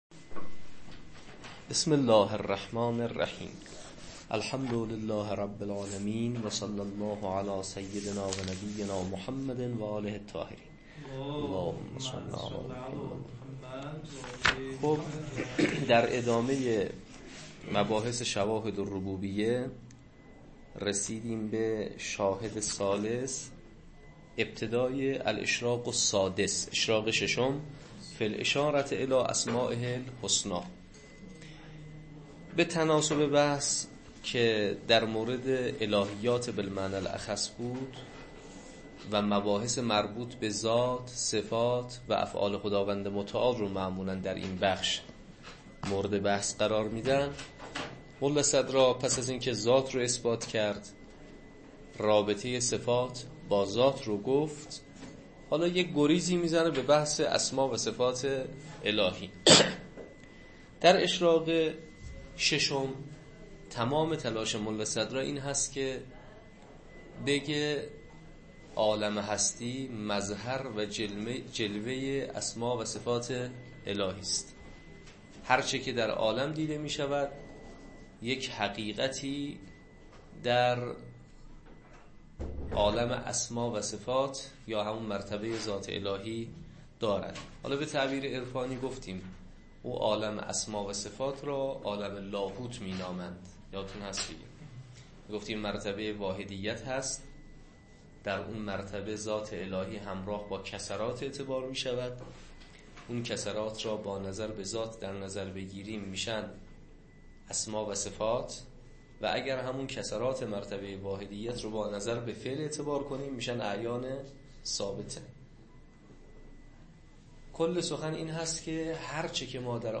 شواهد الربوبیه تدریس